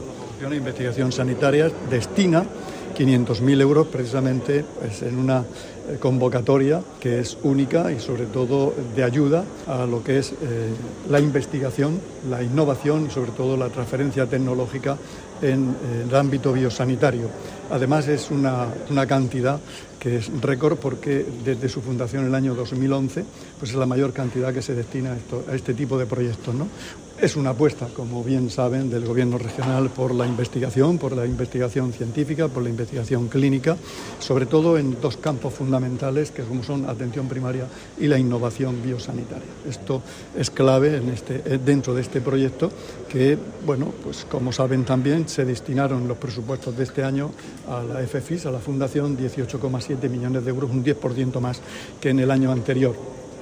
Declaraciones del consejero de Salud sobre la convocatoria de ayudas para investigación de la Fundación para la Formación e Investigación Sanitarias (FFIS). [mp3]